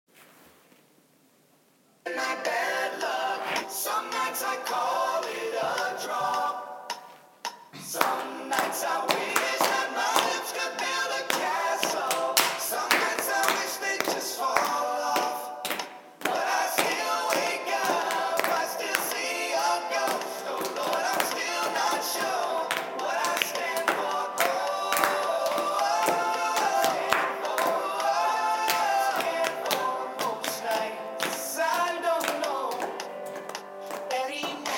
some nights with backing clap